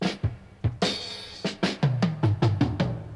Chopped Fill 11.wav